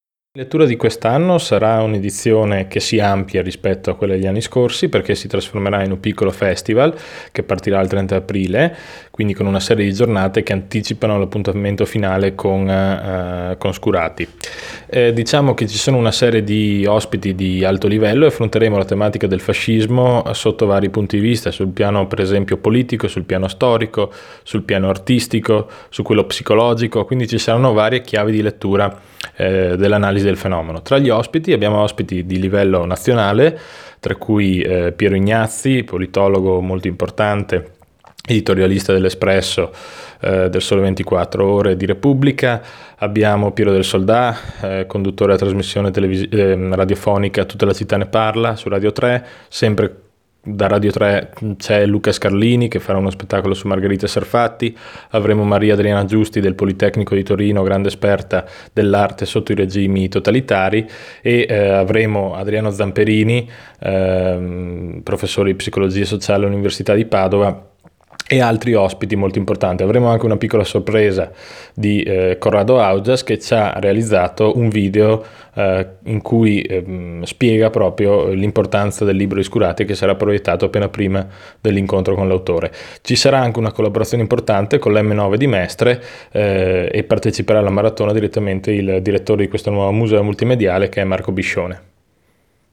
AI MICROFONI DI RADIO PIU, L’ASSESSORE ALLA CULTURA ALESSANDRO DEL BIANCO